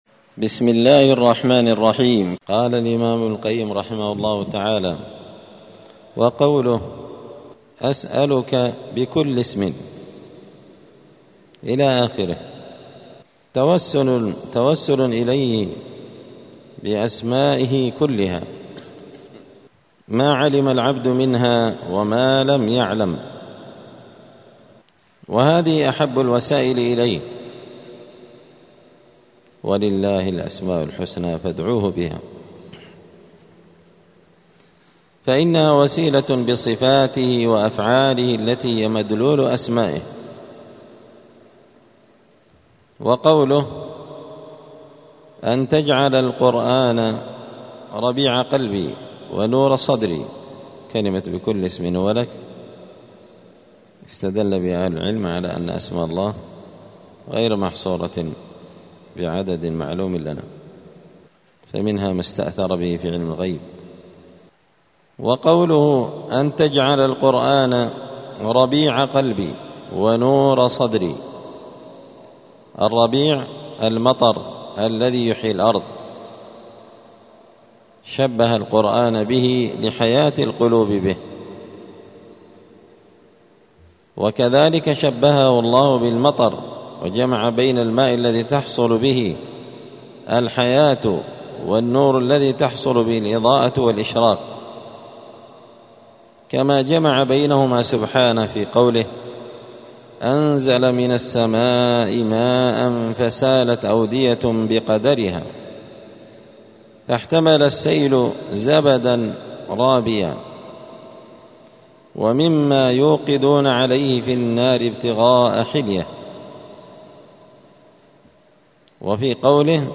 دار الحديث السلفية بمسجد الفرقان قشن المهرة اليمن 📌الدروس الأسبوعية